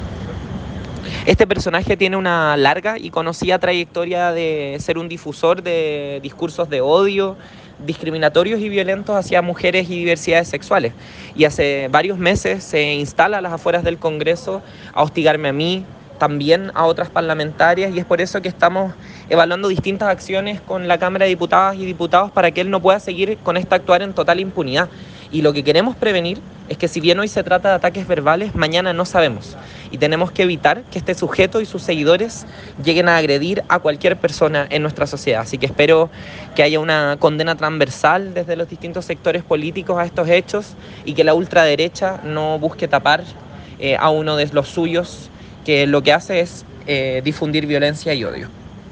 Haciendo uso de un parlante y un micrófono, el autodenominado líder religioso atacó verbalmente a la parlamentaria con palabras como “Señor Schneider”, “inmundicia” y “perversiones”.
En él, se evidencia cómo, incluso, un funcionario policial intenta calmar al predicador.